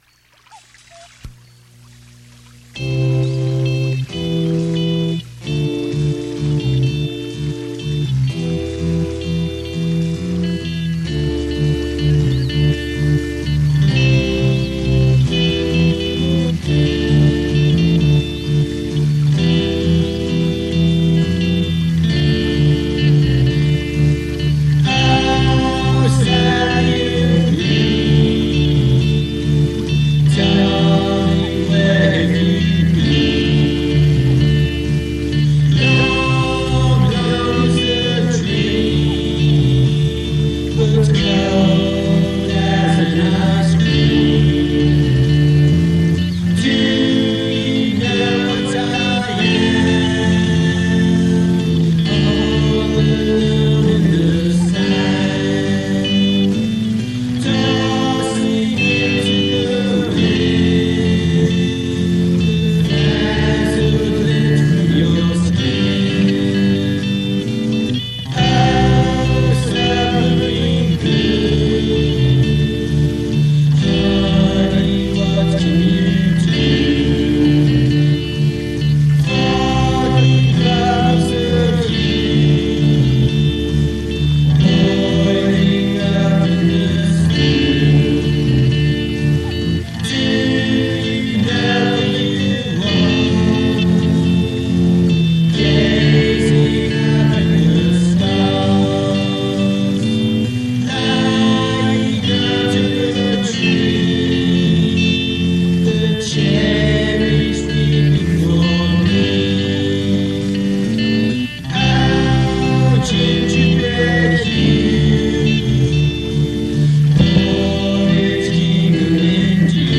demo songs